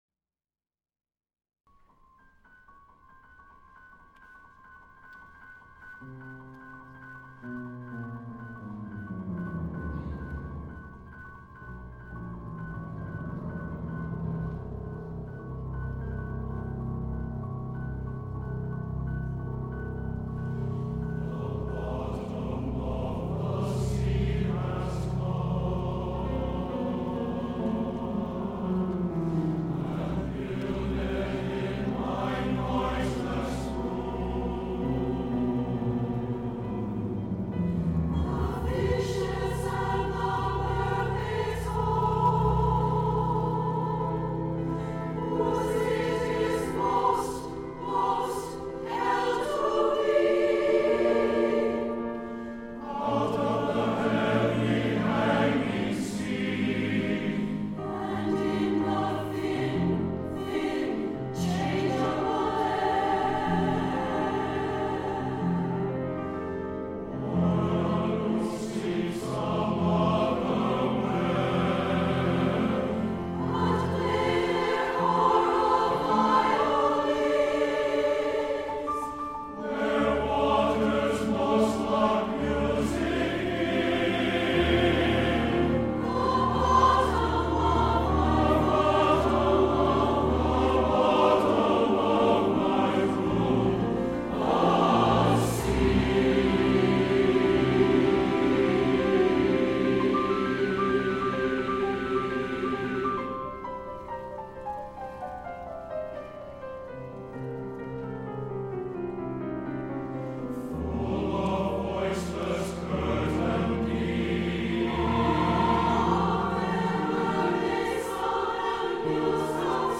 for SATB Chorus and Piano (2004)
[The orchestras play a waltz.] This song is characterized by descending lines into the depths of the piano and choral ranges.